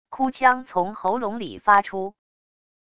哭腔从喉咙里发出wav音频